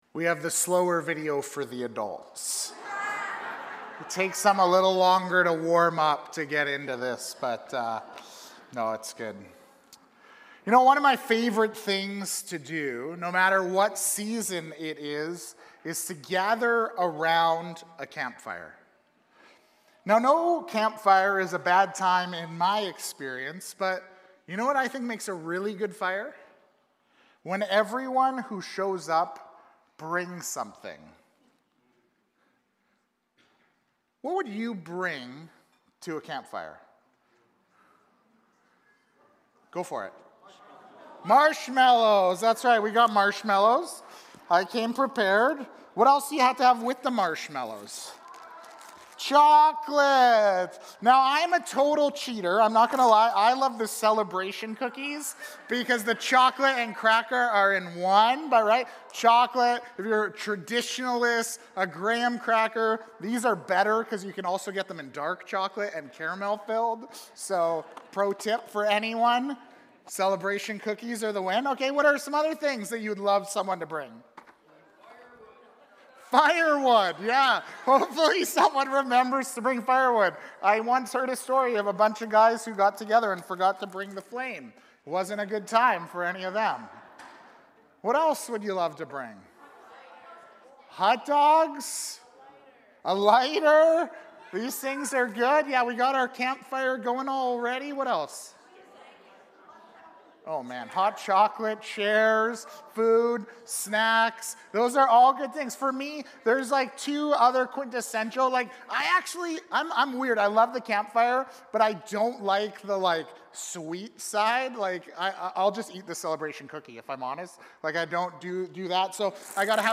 Immanuel Church Sermons | Immanuel Fellowship Baptist Church